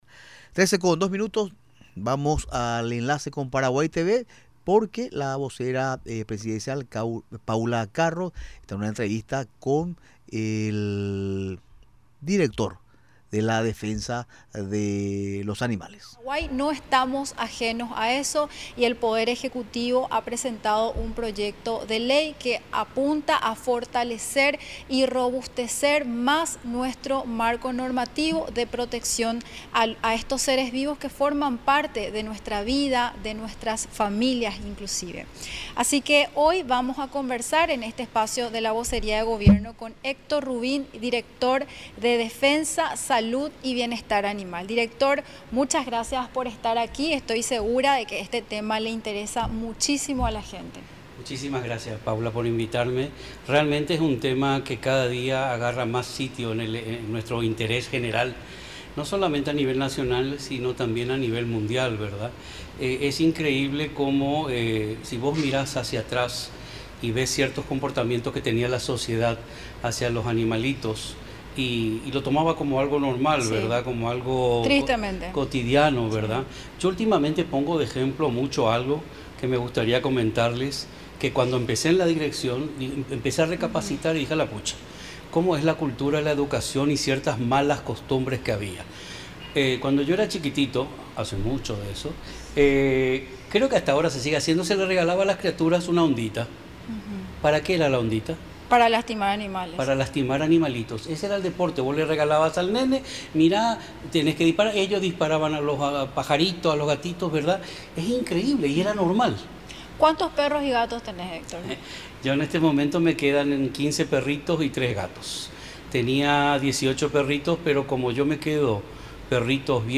Al respecto de las medidas de prevención, Rubín señala que en el proyecto de ley se contempla que en las escuelas se enseñe sobre bienestar animal, el respeto a los animales, que impactará de forma directa en el comportamiento de los niños, dijo en conversación con la vocera de Gobierno, Paula Carro.